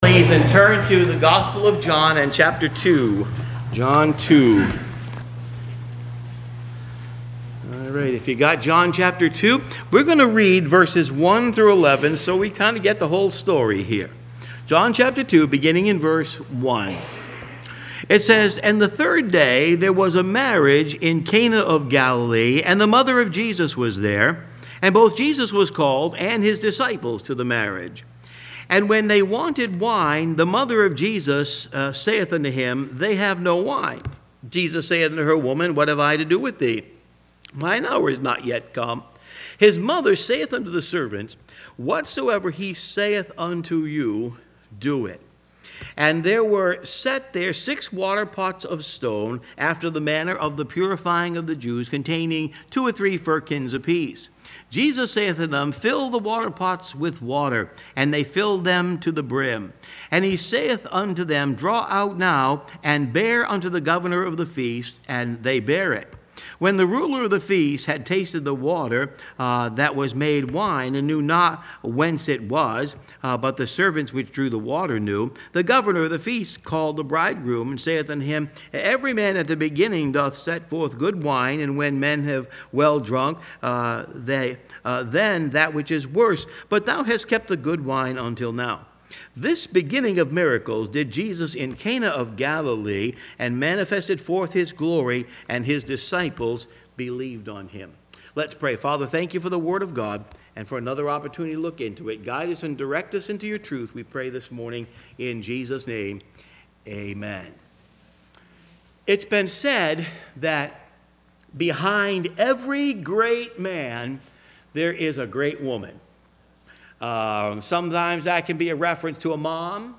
A One Line Sermon - Pascoag Community Baptist Church